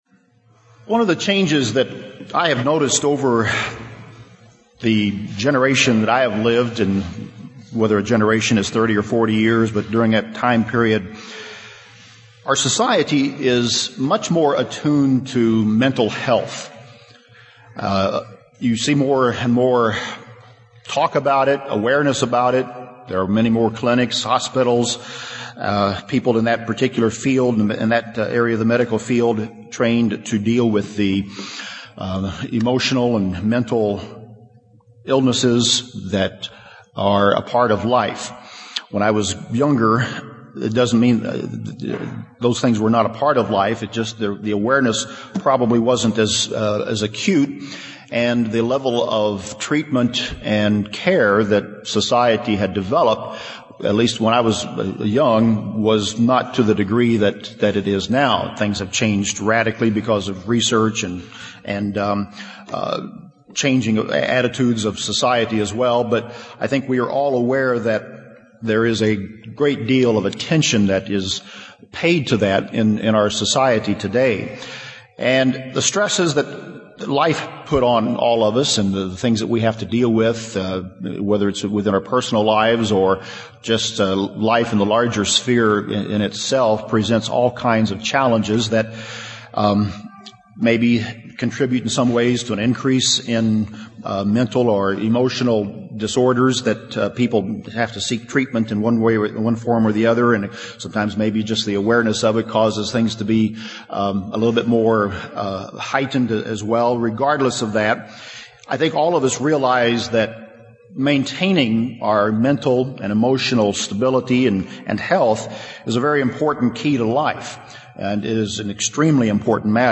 In this sermon, we'll explore 7 steps to maintaining emotional and mental health.